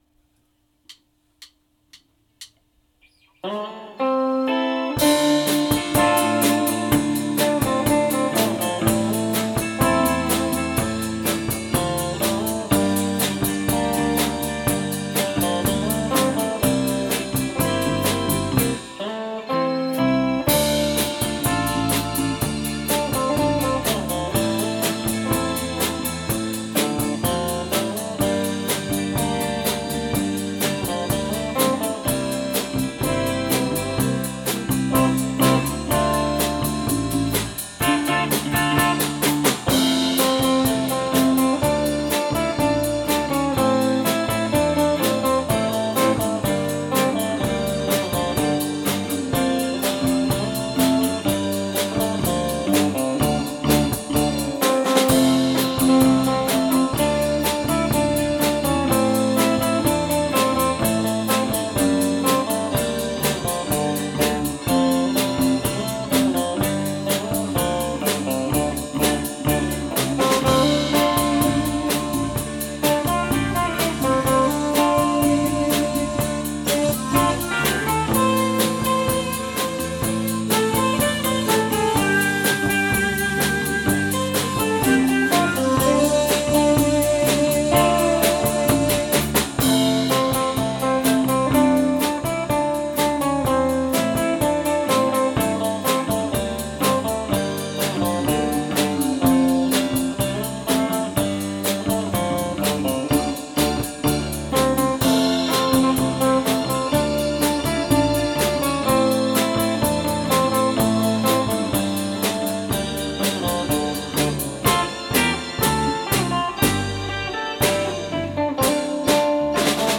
場所：ベンチャーズハウス「六絃